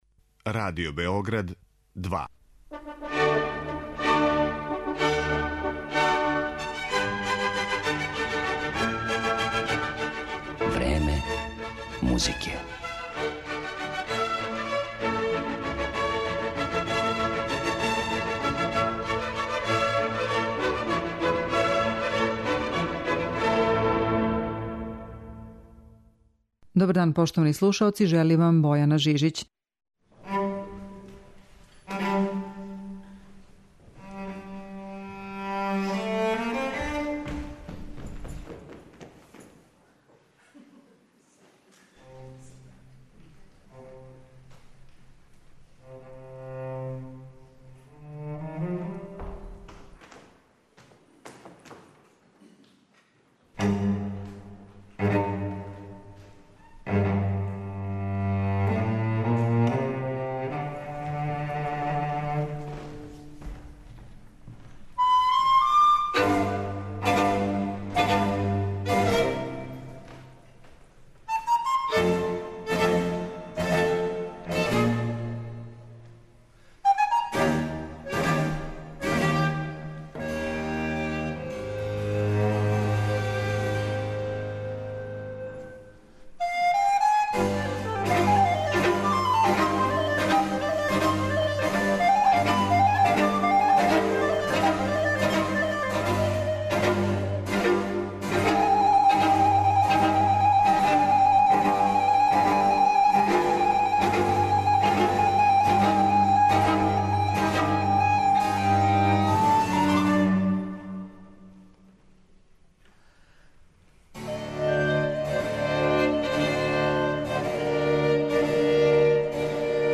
енглеском ансамблу
музиком других барокних композитора